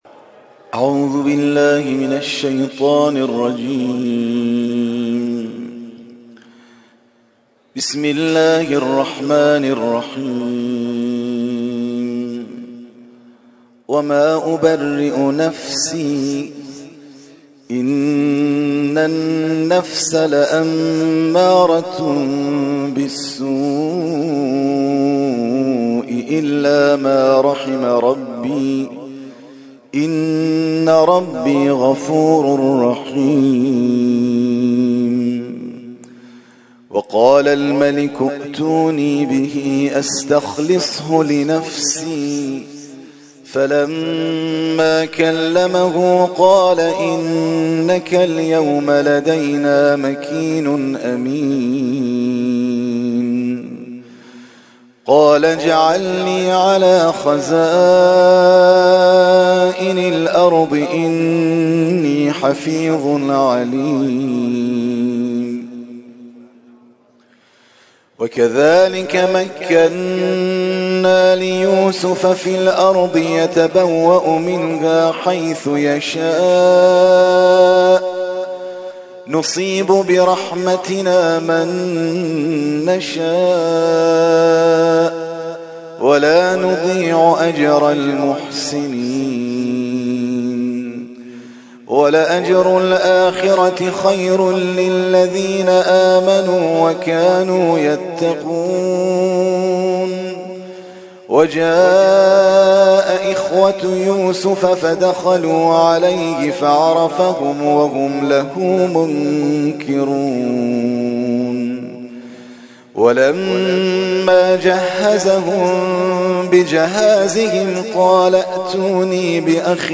ترتیل خوانی جزء ۱۳ قرآن کریم در سال ۱۳۹۳